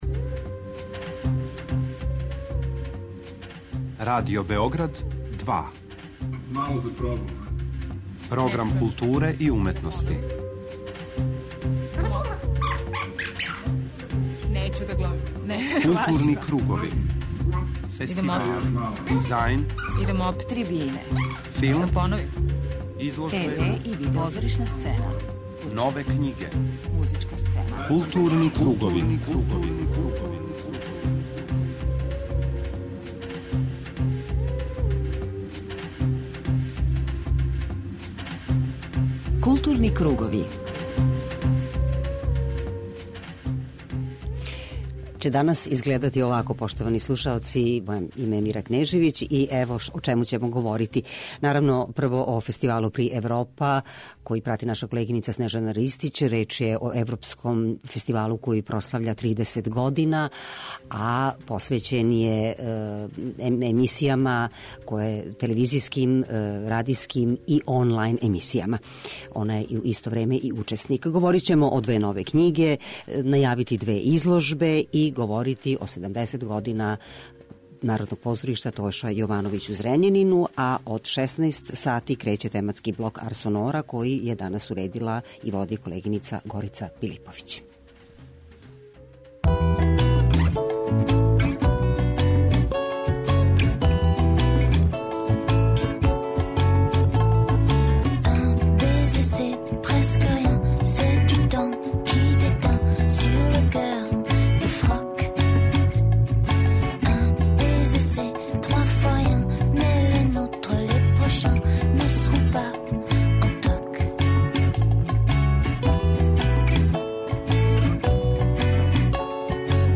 преузми : 39.23 MB Културни кругови Autor: Група аутора Централна културно-уметничка емисија Радио Београда 2.